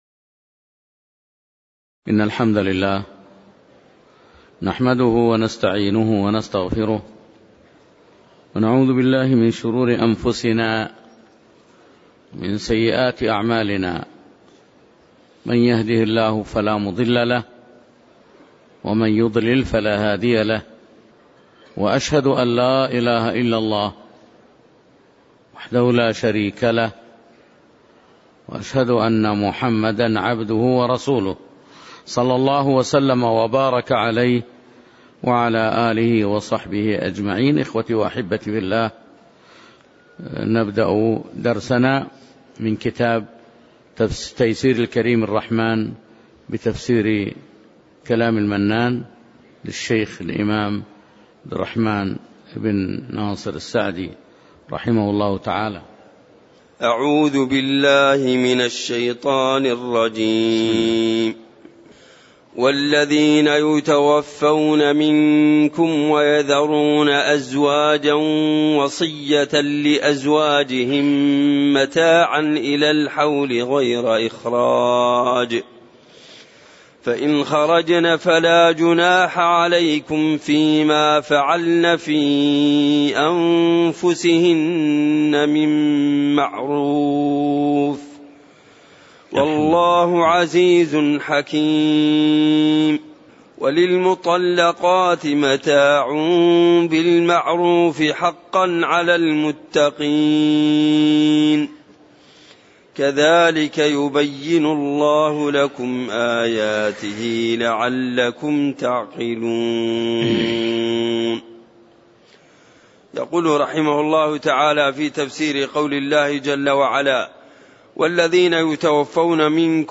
تاريخ النشر ٥ ربيع الثاني ١٤٣٩ هـ المكان: المسجد النبوي الشيخ